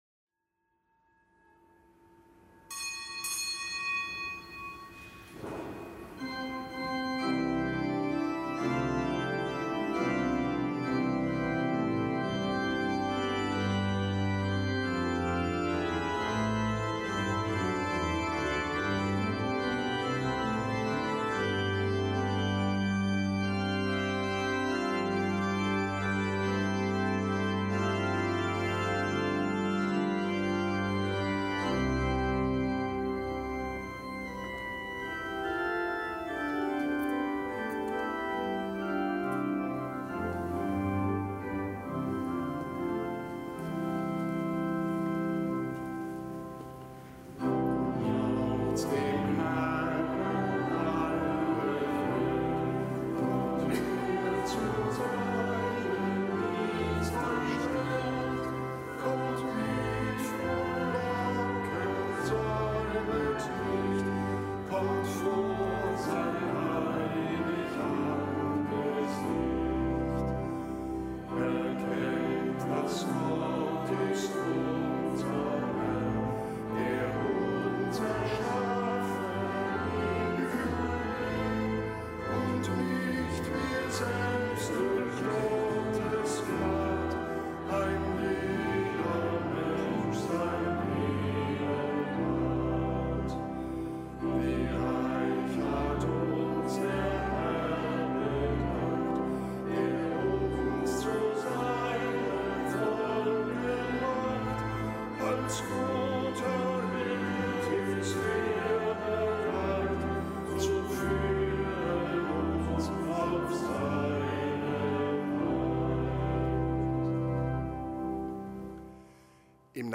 Kapitelsmesse am Montag der elften Woche im Jahreskreis
Kapitelsmesse aus dem Kölner Dom am Montag der elften Woche im Jahreskreis, nichtgebotener Gedenktag Hl. Benno, Bischof von Meißen (RK).